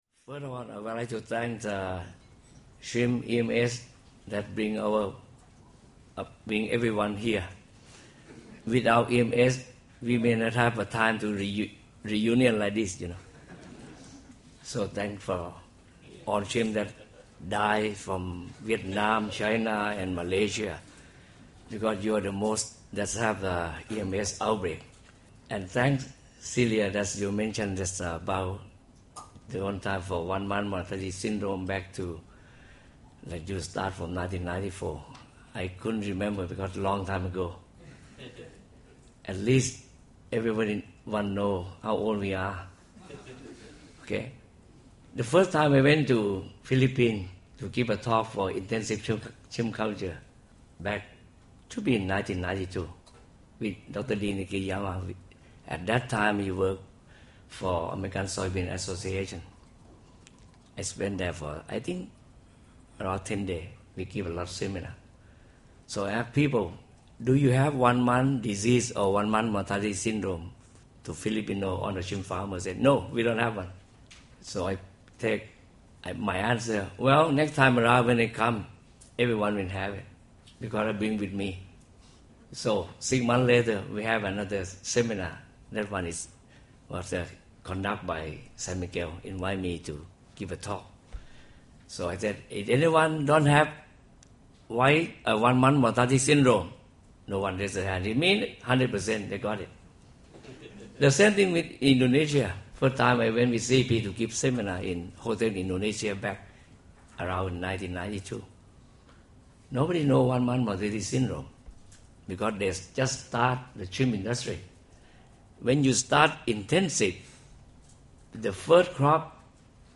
Presentation on the management of acute hepatopancreatic necrosis syndrome or "early mortality syndrome".